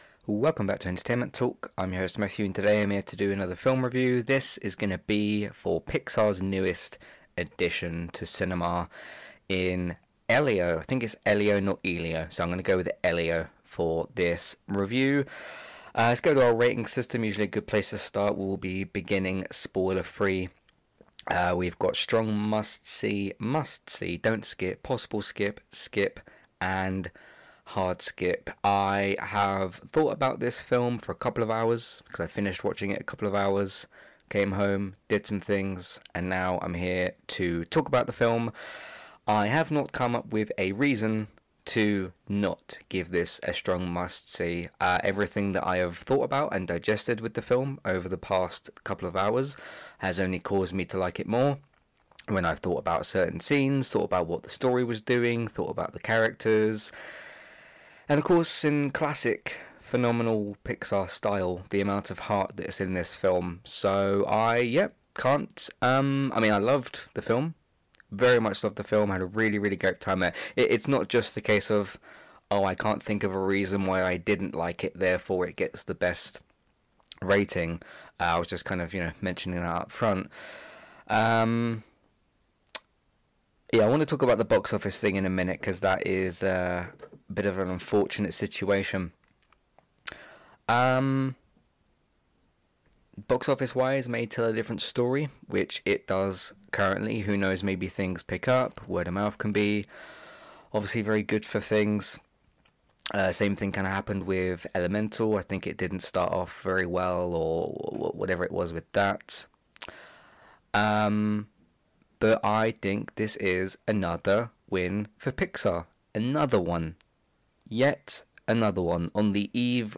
Apologies for the audio on this one, I did try editing but will be looking for a new mic